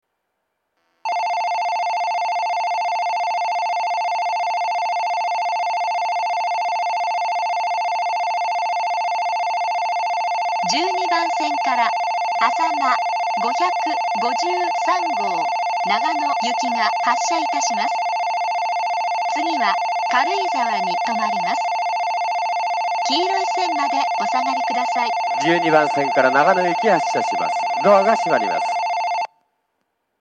在来線では全ホーム同じ発車メロディーが流れますが、新幹線ホームは全ホーム同じ音色のベルが流れます。
２０１２年頃に放送装置を更新したようで、ベルの音質が向上しています。
１２番線発車ベル 主に上越新幹線が使用するホームです。
あさま５５３号長野行の放送です。